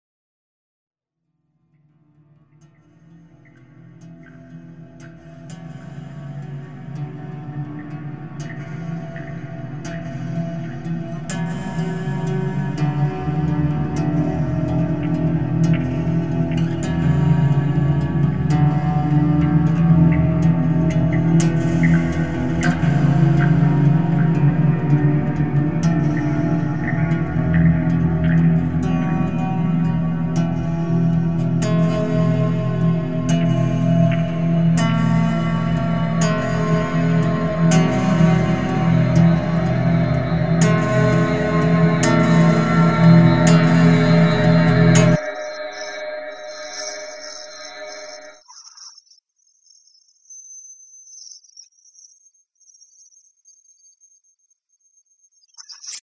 File under: Experimental / Minimal Ambient / Drone Music